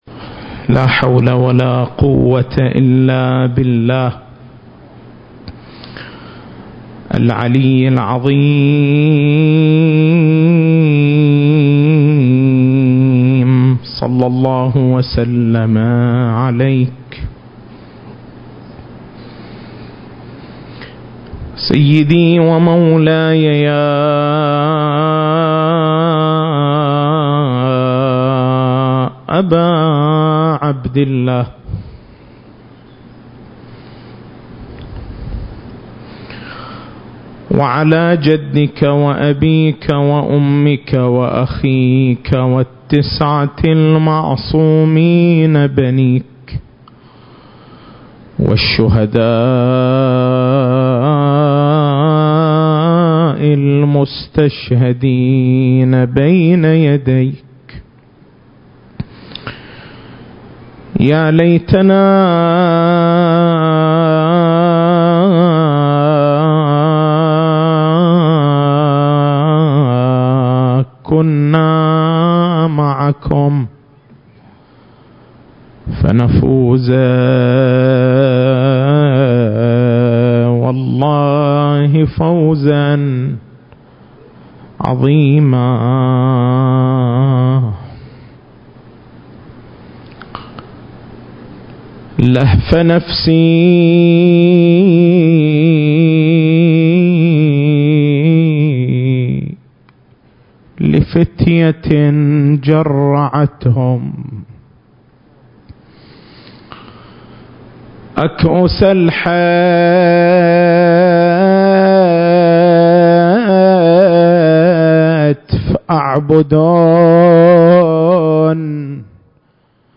المكان: حسينية الحاج حبيب العمران التاريخ: 1440 للهجرة